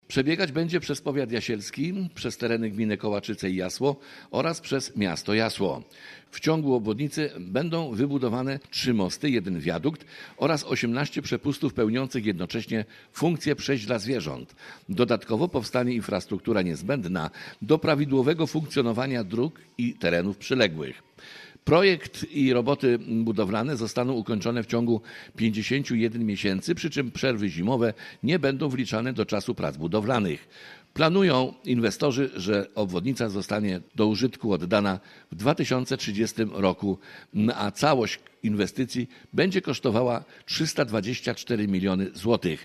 Rusza budowa zachodniej obwodnicy Jasła za 324 mln zł • Relacje reporterskie • Polskie Radio Rzeszów